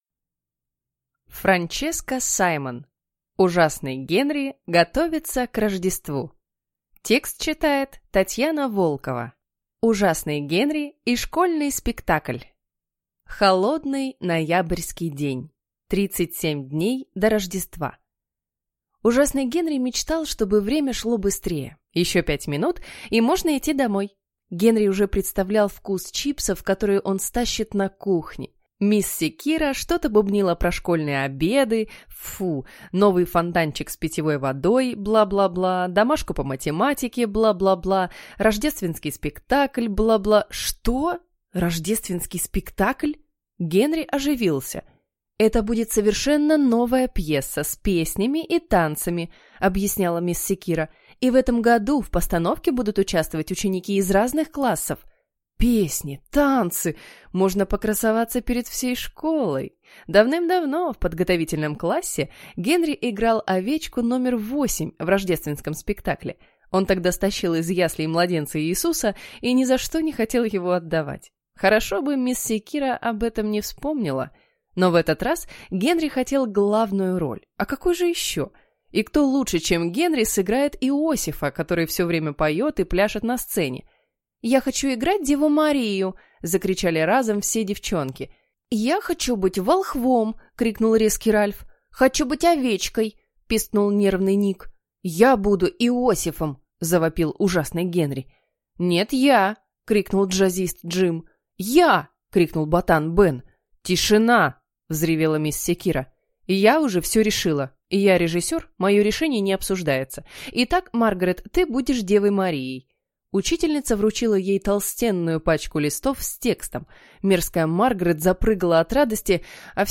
Аудиокнига Ужасный Генри готовится к Рождеству | Библиотека аудиокниг